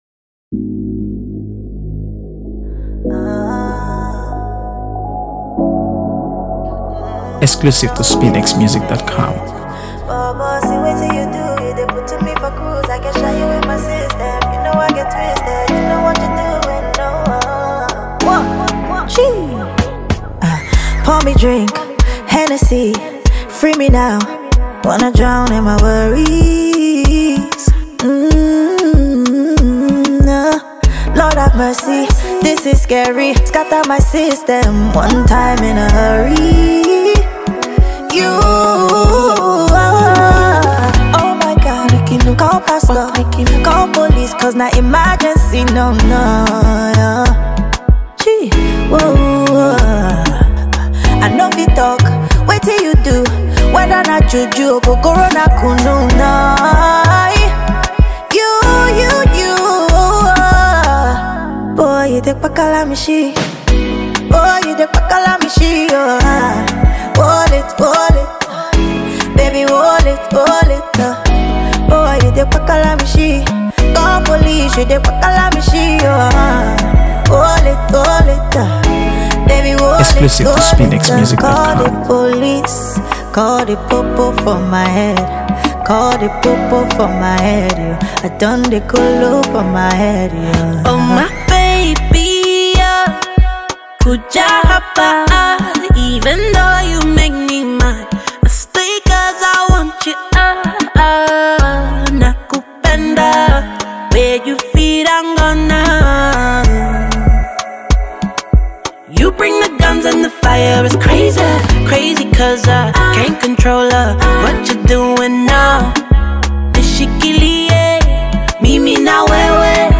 AfroBeats | AfroBeats songs
soulful vocals
blend Afrobeats with rich, emotive storytelling
adds depth and harmony